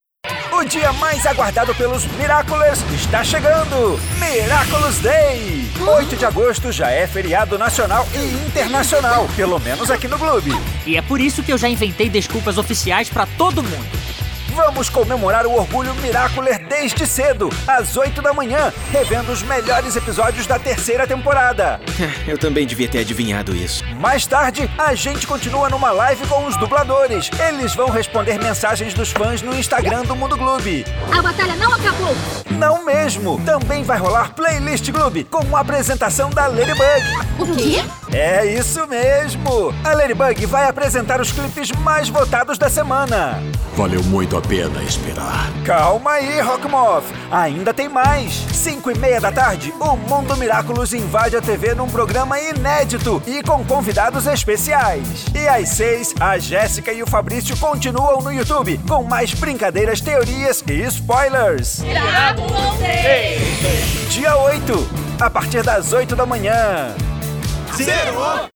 Masculino
Voz Jovem 01:08
• Tenho voz leve e versátil, e interpretação mais despojada.